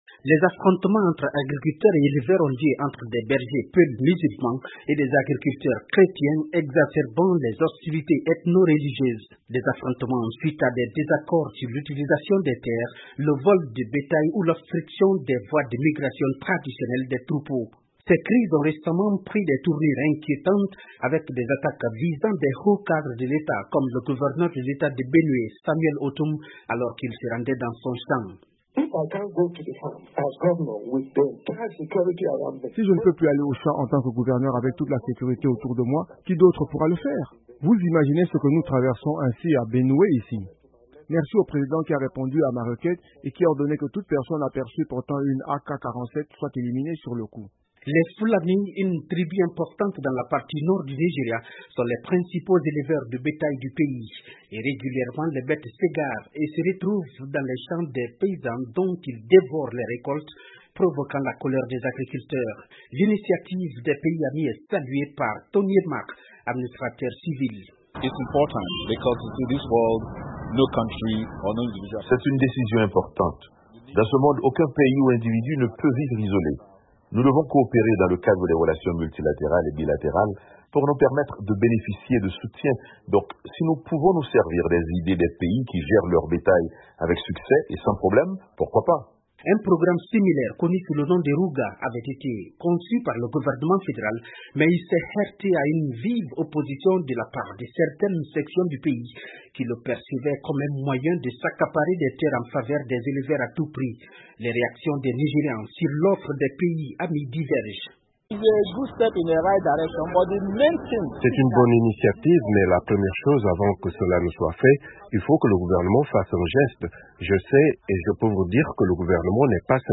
Réponses dans ce reportage